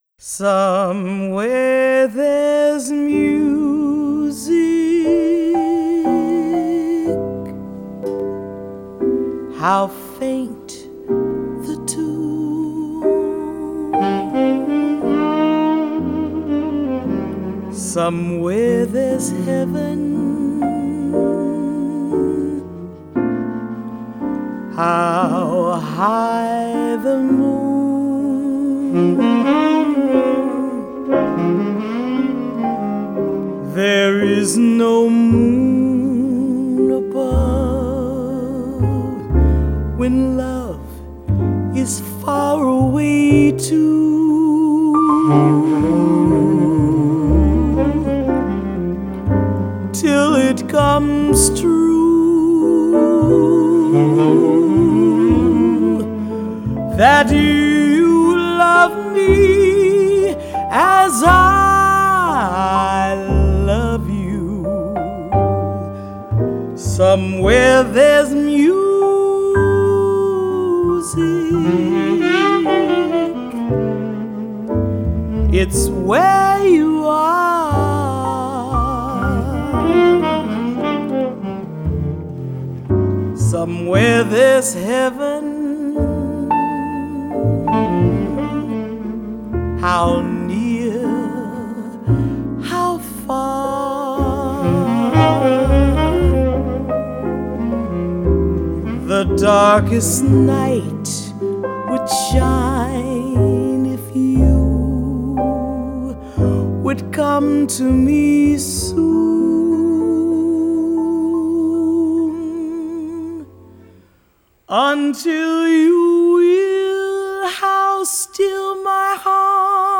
jazz singer.